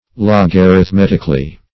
Search Result for " logarithmetically" : The Collaborative International Dictionary of English v.0.48: Logarithmetically \Log`a*rith*met"ic*al*ly\, adv.